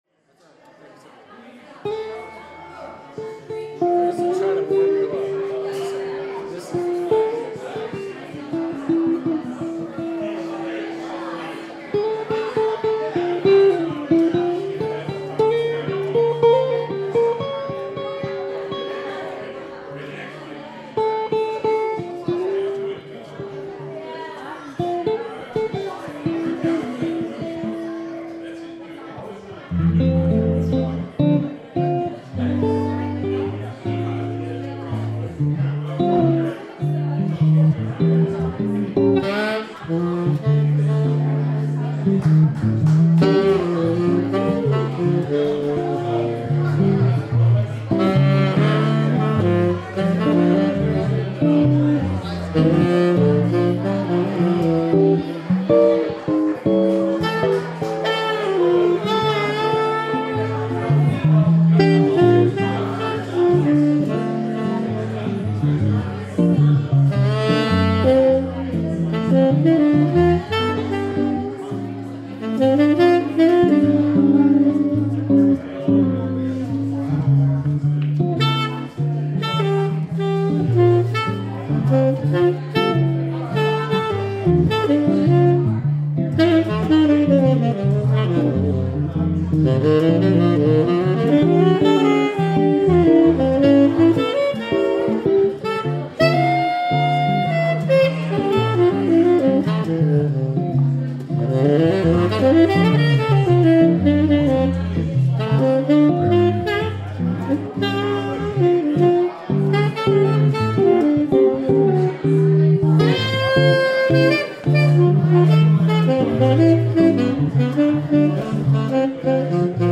Solo Jazz Guitar